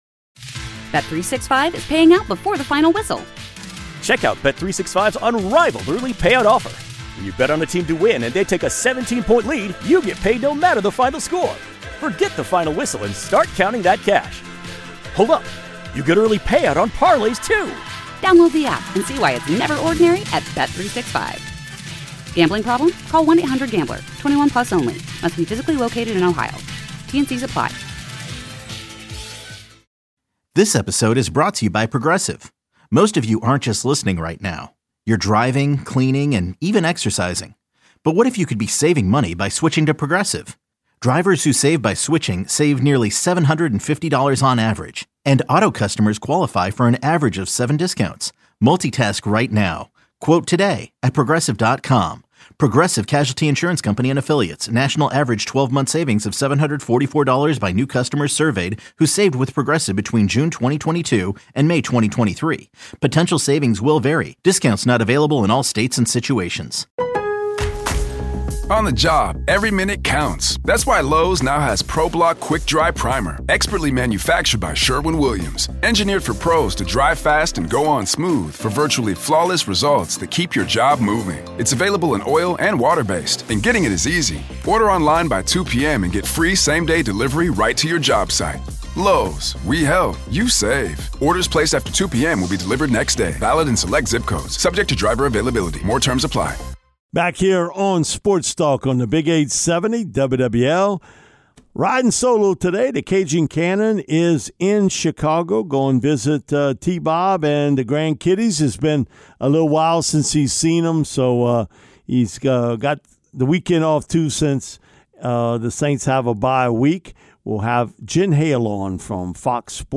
Fox Sports reporter Jen Hale